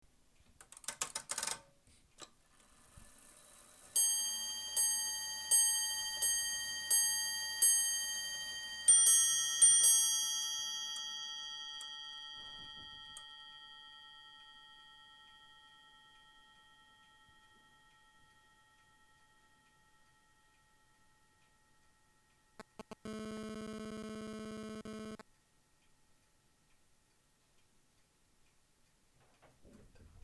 Pendule neuchâteloise Louis XVI [1770 - 1790] La Chaux-de-Fonds (Neuchâtel canton - Suisse)